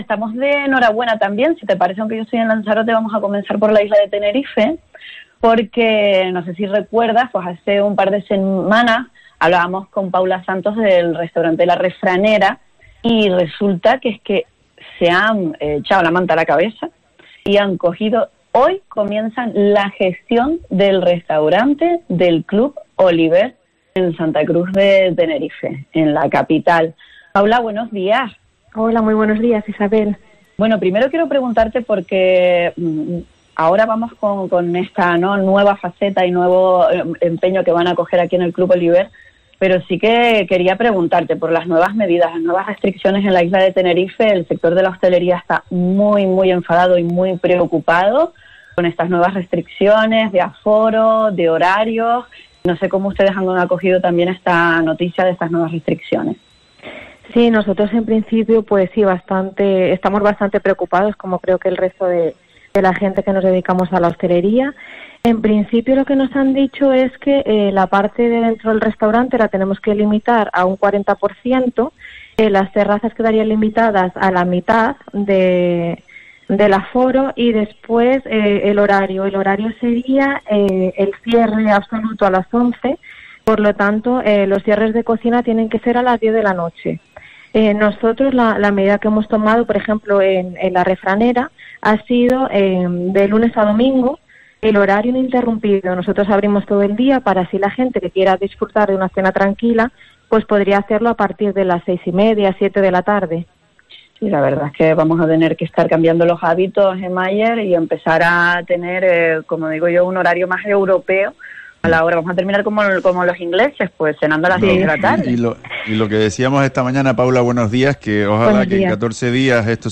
"Nos dieron la oportunidad de gestionarlo y decidimos dar el paso porque las oportunidades se tienen que aprovechar cuando llegan, porque si no luego uno se arrepiente", afirma decidida la empresaria en los micrófonos de COPE Canarias.